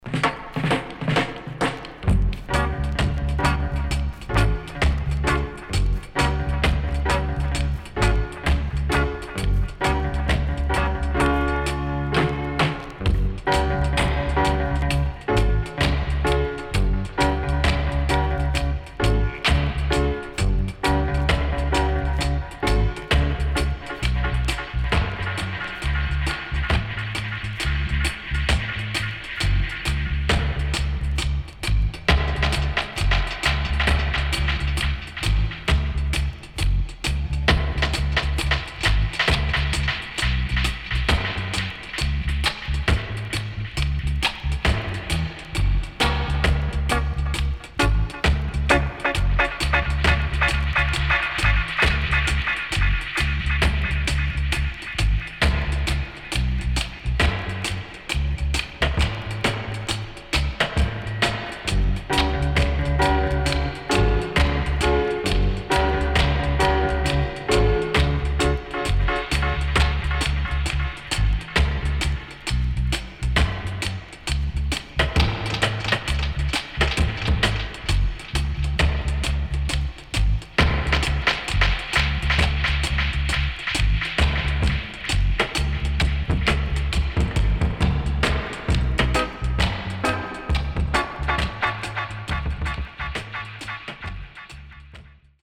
HOME > DUB
SIDE A:少しチリノイズ入りますが良好です。
SIDE B:少しチリノイズ入りますが良好です。